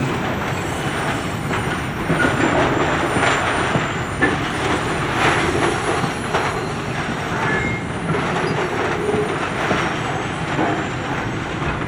Train
• Train at 20% health.
Snowplow_Forbnl_eotl_train_lastlegs.wav